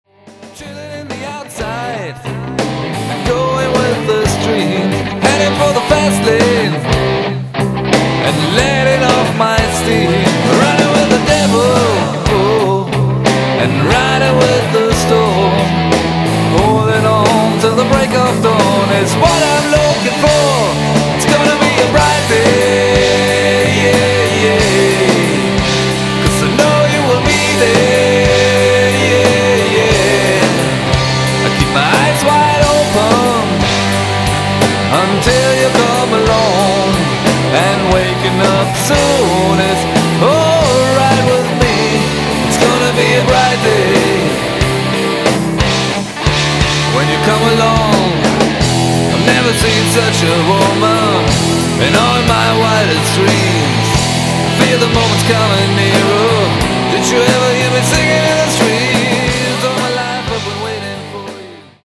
Gesang
Drums
Bass
Keys
Gitarre